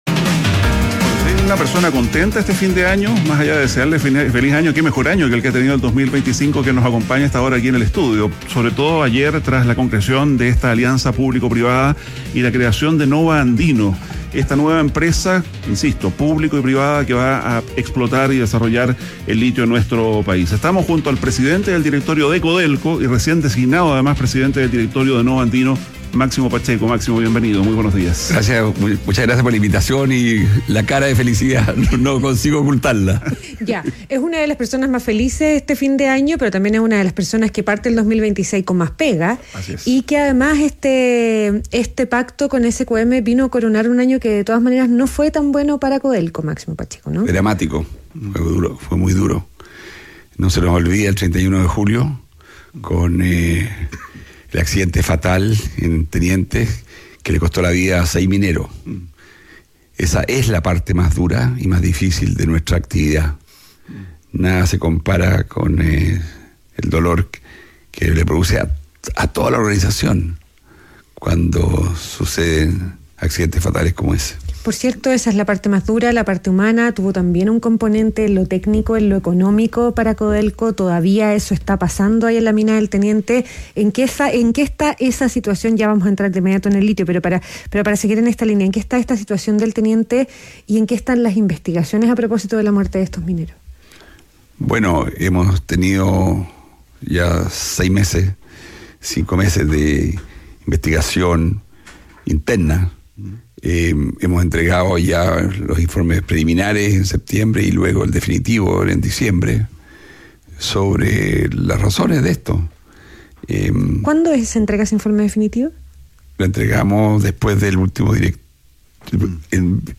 ADN Hoy - Entrevista a Máximo Pacheco, presidente del directorio de Codelco